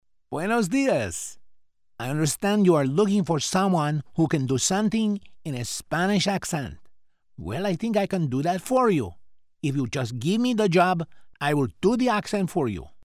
Mature Adult
Has Own Studio
Spanish - Iberian